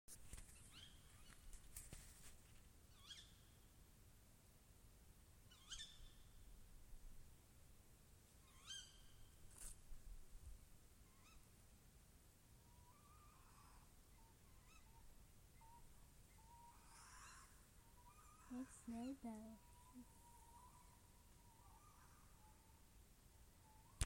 Birds -> Owls ->
Tawny Owl, Strix aluco
StatusVoice, calls heard